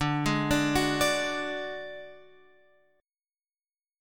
D Major Flat 5th